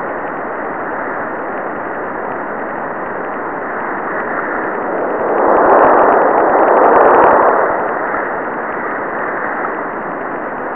Recordings of Concorde
Angle of arrival of the infrasonic signal from Concorde, flying from the US to Europe, recorded at Luleå, Sweden (65.8N, 22.5E) on February 6, 1995 at midnight.
Unix-format(~59K)) generated by Concorde is frequency transformed (x306) in order to be audible.